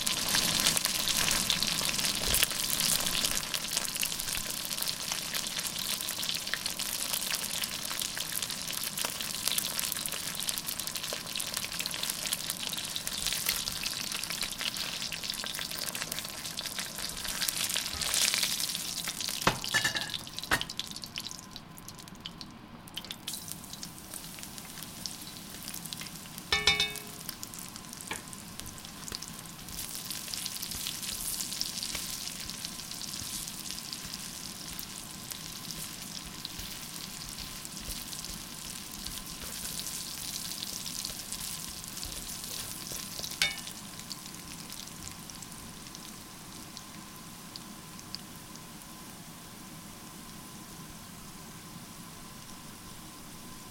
SFX炒菜09(Stir fry 09)音效下载